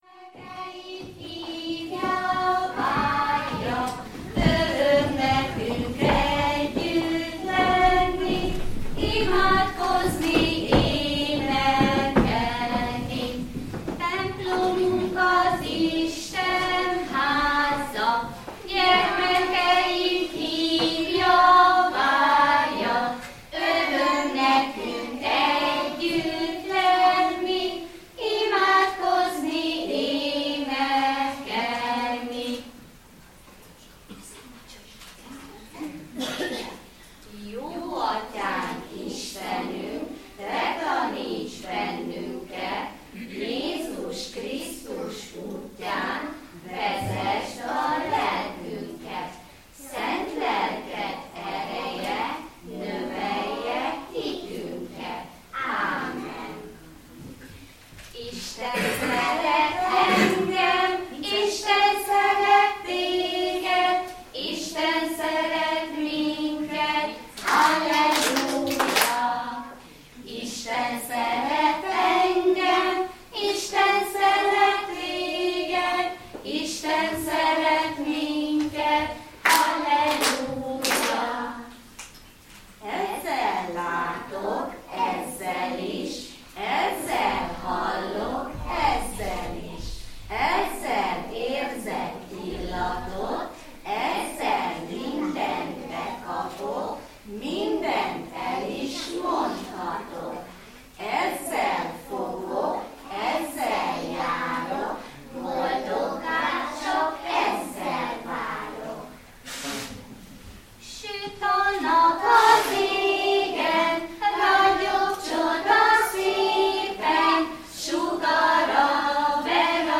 Családi istentisztelet a Jó Pásztor Református Óvoda Csillag csoportjának szolgálatával.